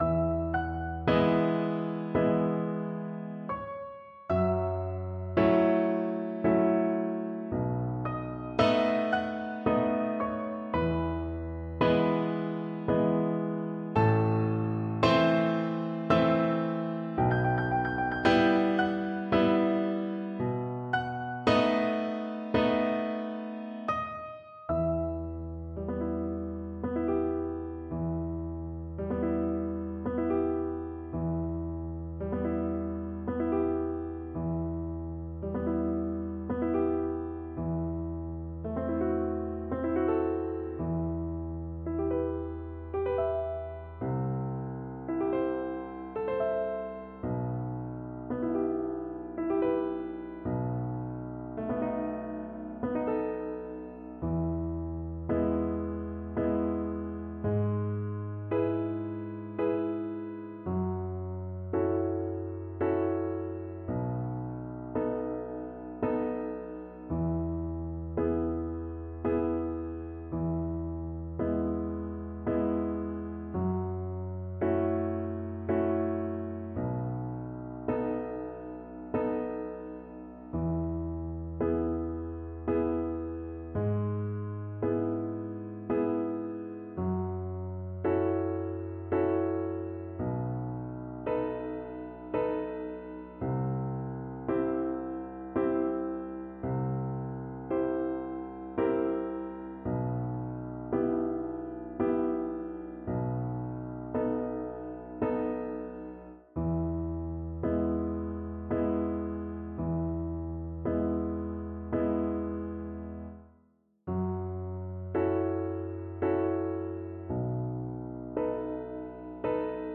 3/4 (View more 3/4 Music)
Andantino = c. 86 (View more music marked Andantino)
Neapolitan Songs for Violin